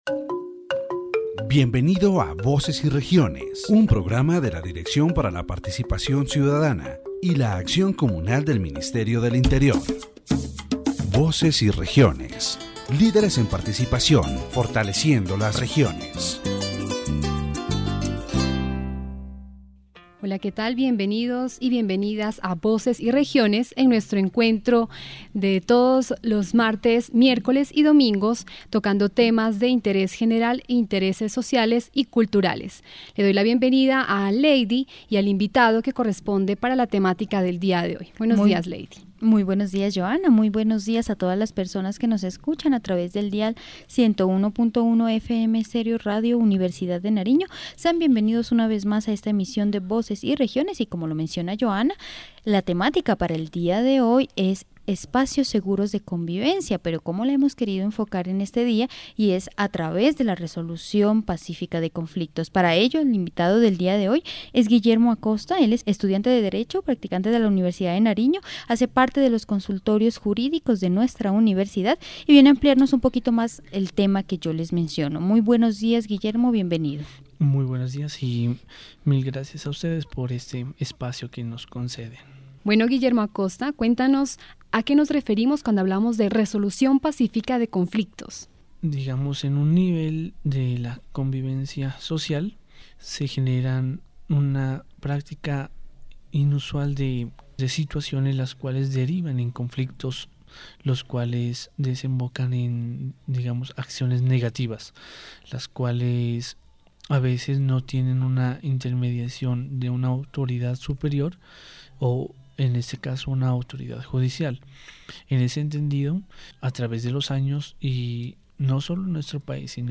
The radio program "Voices and Regions" is focused on promoting citizen participation and strengthening the regions of Colombia. In this broadcast, the topic of peaceful conflict resolution is addressed as a means to create safe spaces for coexistence.